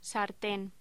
Locución: Sartén
voz
Sonidos: Voz humana